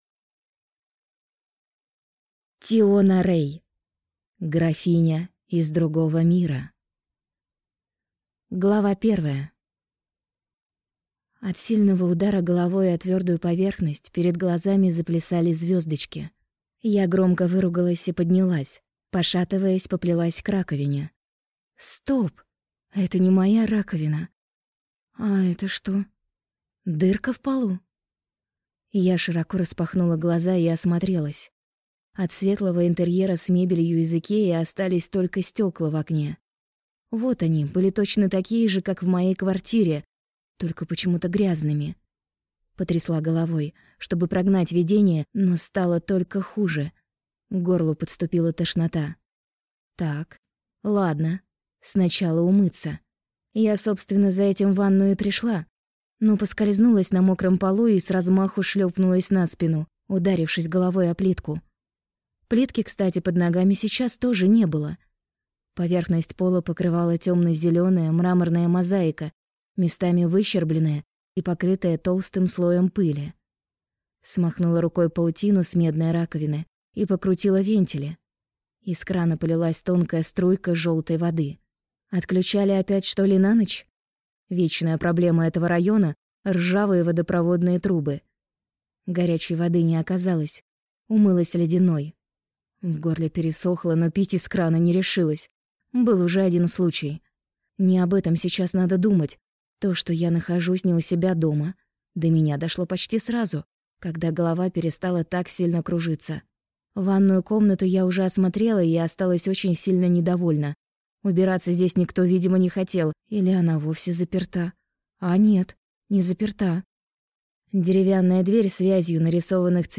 Аудиокнига Графиня из другого мира | Библиотека аудиокниг